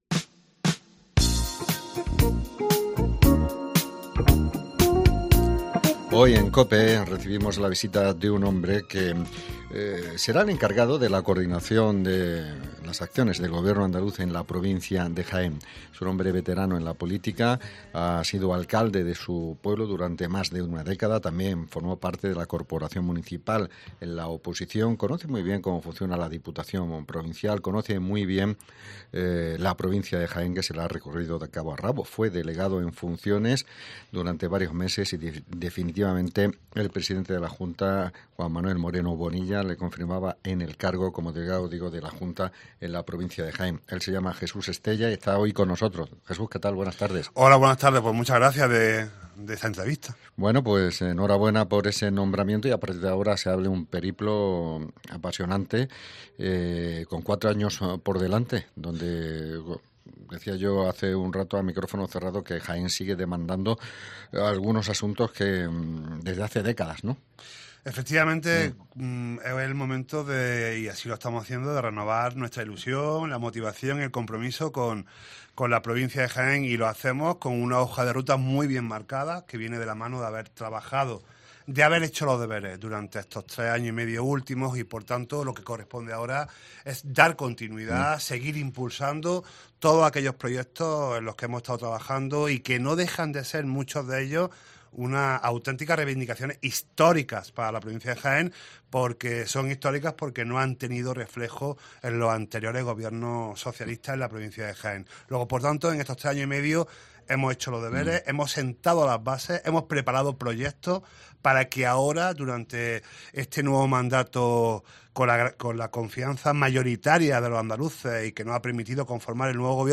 Entrevistamos al nuevo delegado provincial de la Junta en Jaén quien pone de manifiesto que el diálogo y el consenso presiderián su gestión en estos...